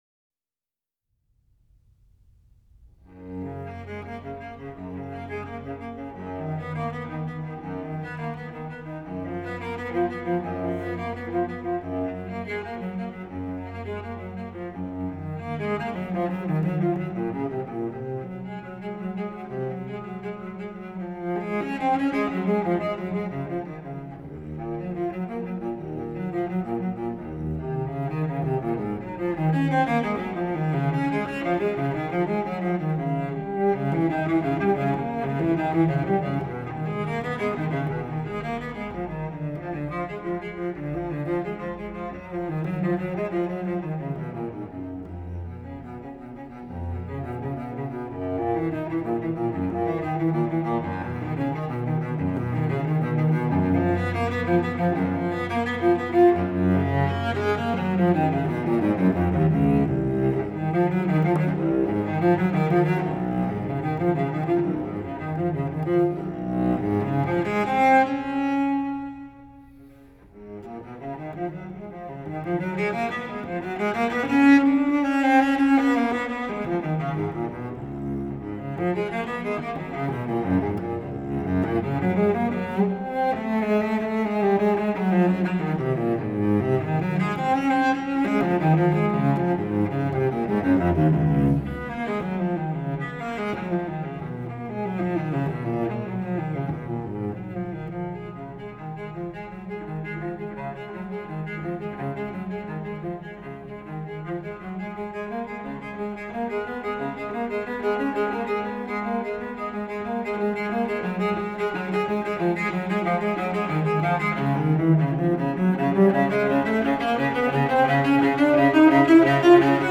音乐可听性无懈可击  名琴音色充满“木头味”